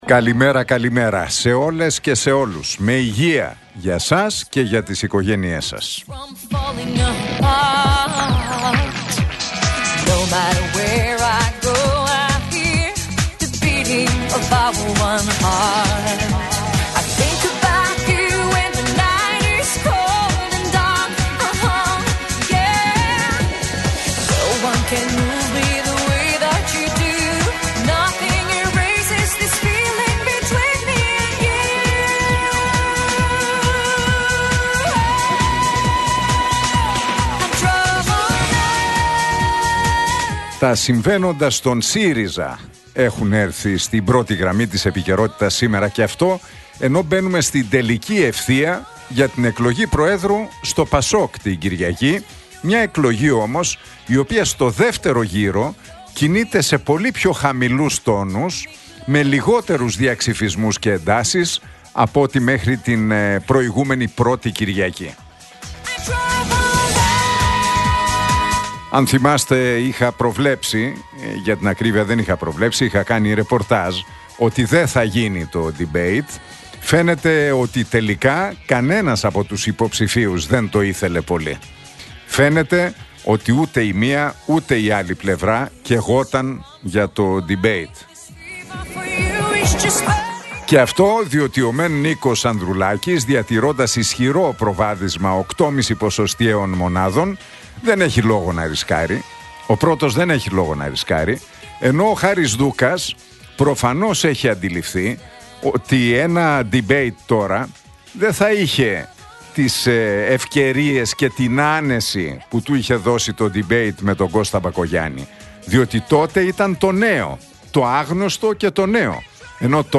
Ακούστε το σχόλιο του Νίκου Χατζηνικολάου στον ραδιοφωνικό σταθμό RealFm 97,8, την Πέμπτη 10 Οκτωβρίου 2024.